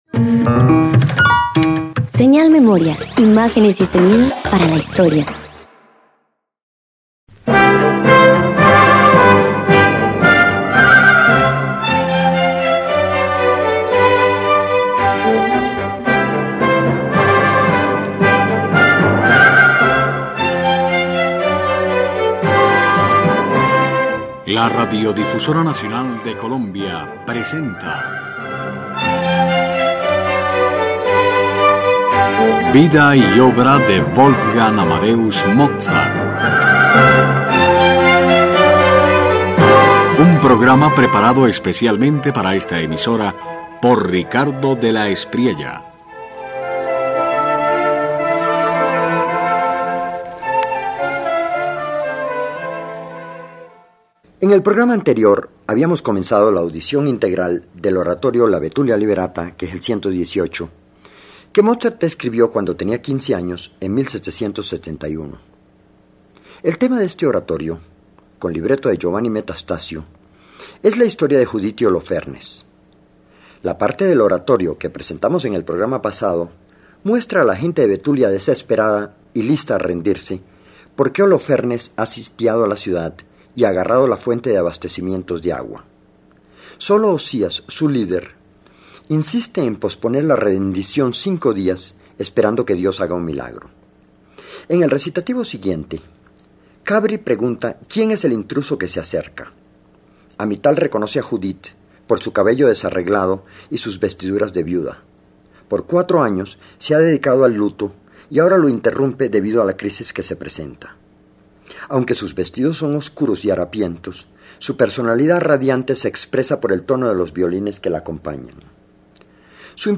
alternando pasajes sombríos y luminosos